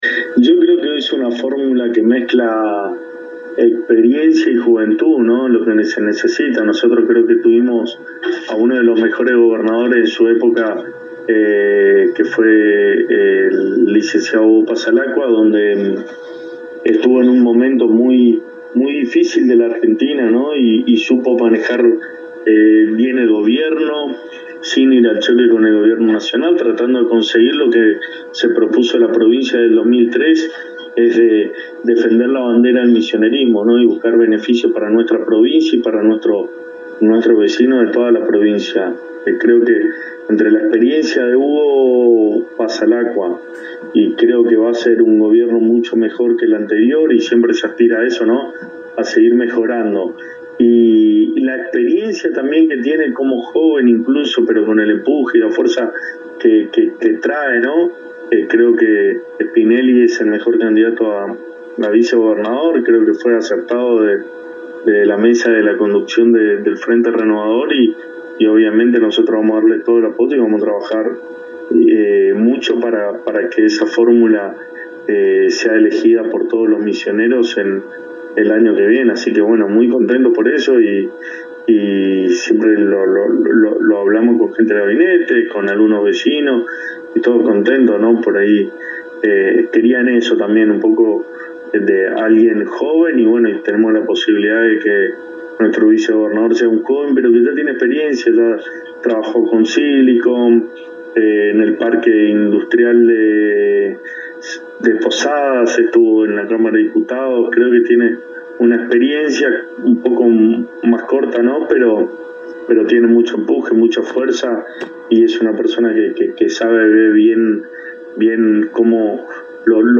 El Secretario de Gobierno en un extenso reportaje concedido a la ANG, manifestó que la fórmula de la Renovación tiene experiencia y juventud.